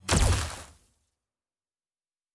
pgs/Assets/Audio/Sci-Fi Sounds/Weapons/Weapon 13 Shoot 3.wav at master
Weapon 13 Shoot 3.wav